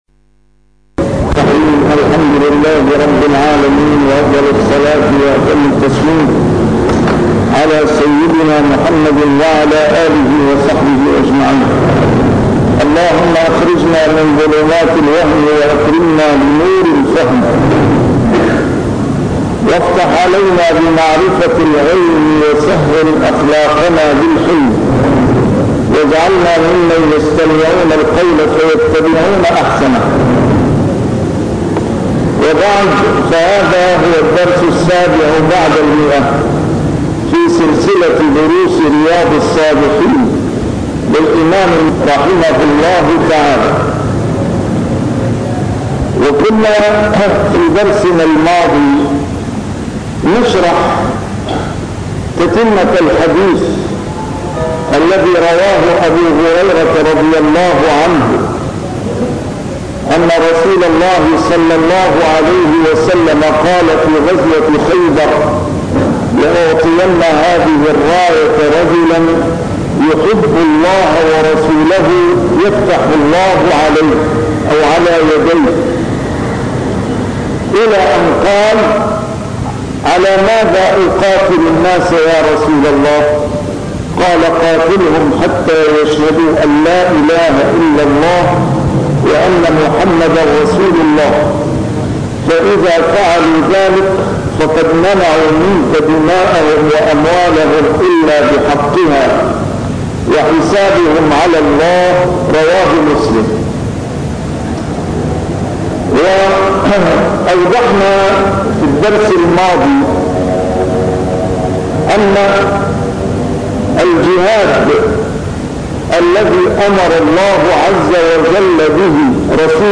A MARTYR SCHOLAR: IMAM MUHAMMAD SAEED RAMADAN AL-BOUTI - الدروس العلمية - شرح كتاب رياض الصالحين - 107- شرح رياض الصالحين: المبادرة إلى الخيرات + المجاهدة